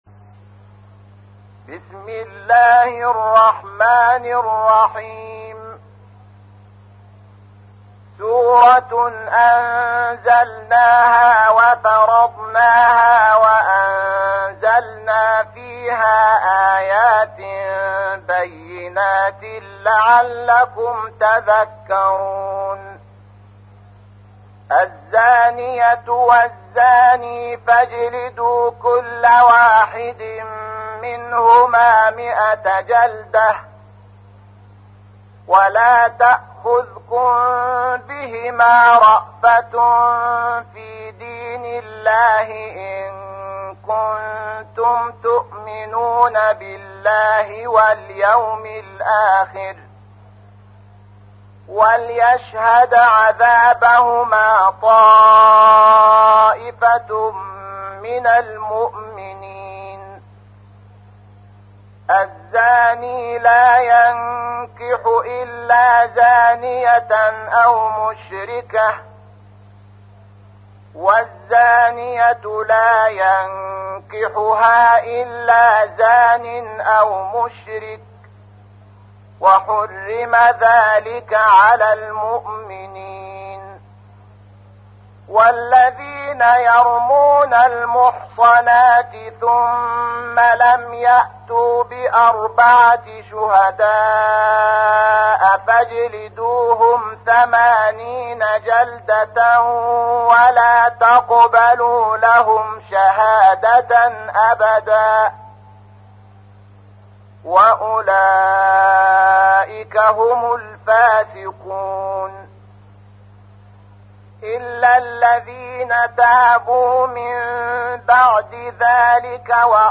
تحميل : 24. سورة النور / القارئ شحات محمد انور / القرآن الكريم / موقع يا حسين